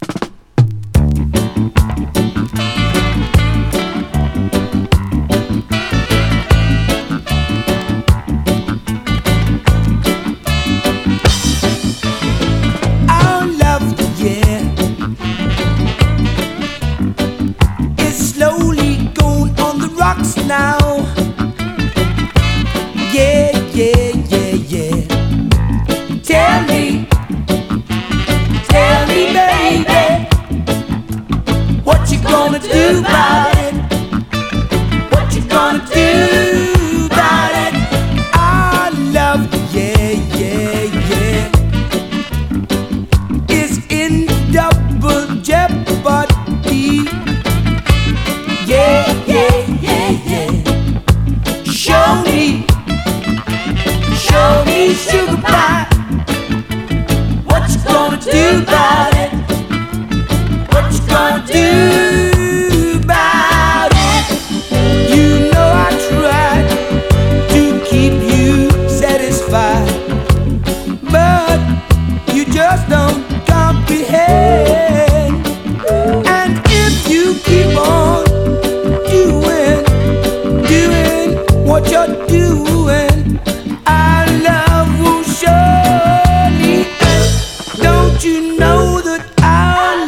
ルーツ・レゲエのレア音源を収録した81年コンピ！